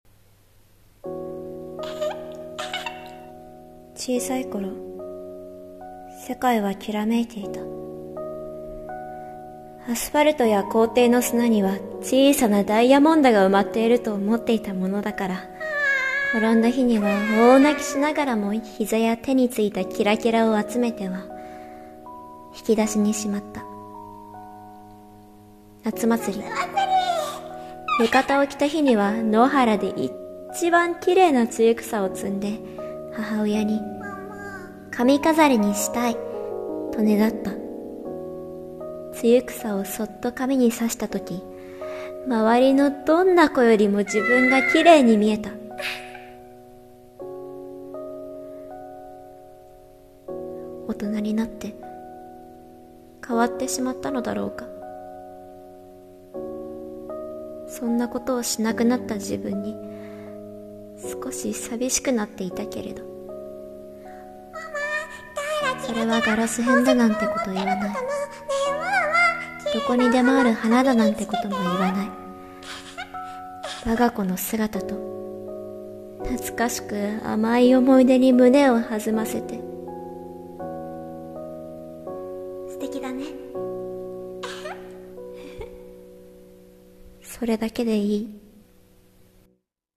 【声劇台本】